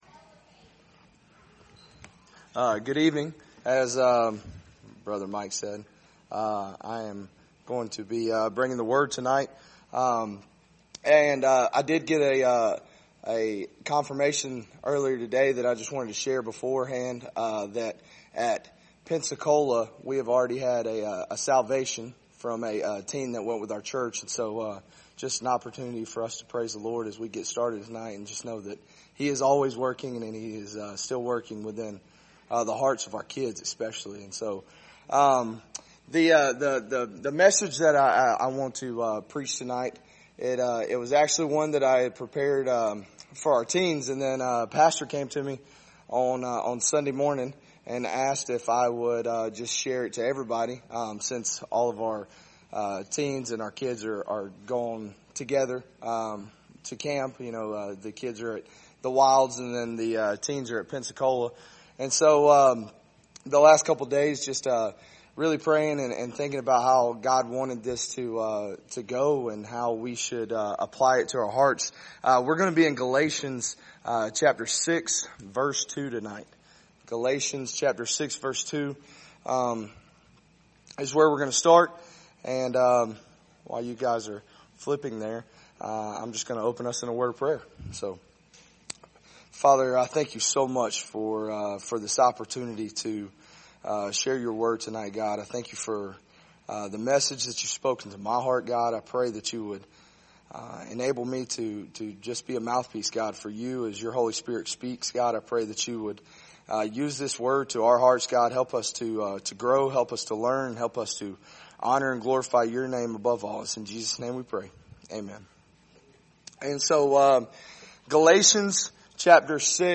Passage: Galatians 6:2 Service Type: Wednesday Evening